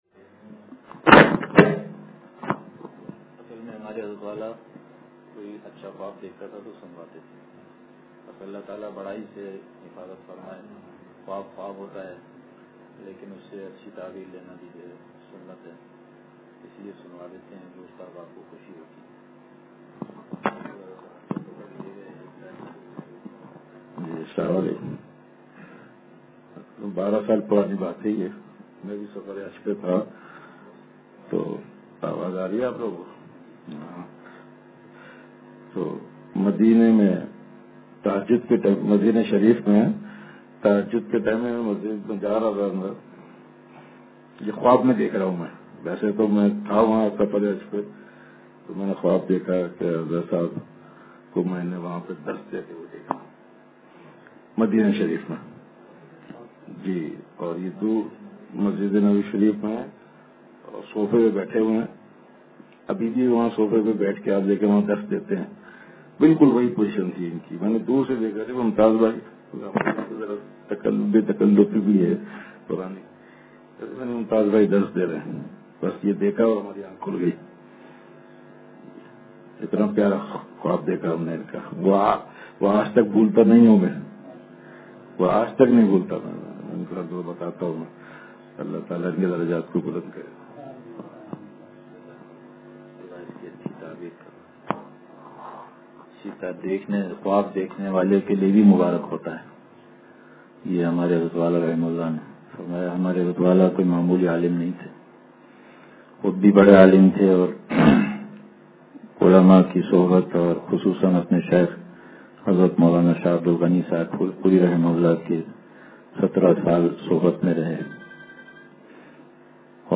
مجلسِ اھلِ دل – اتوار بیان